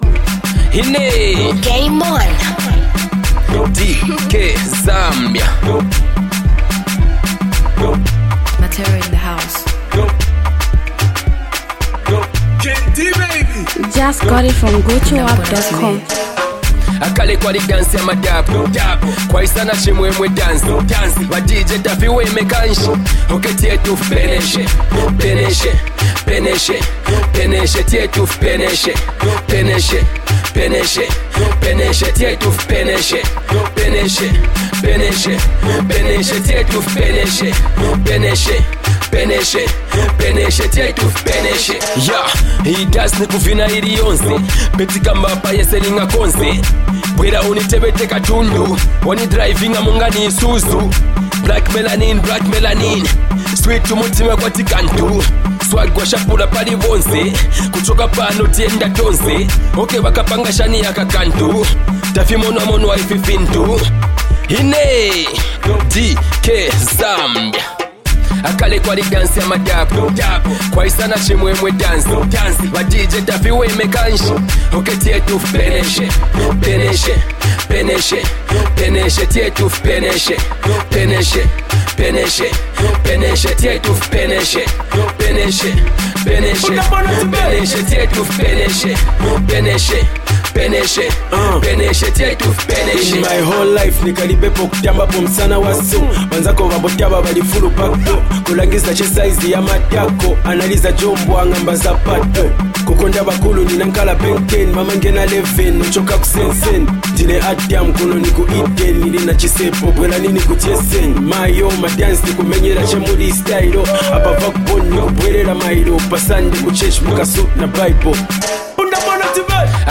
soulful track
emotive delivery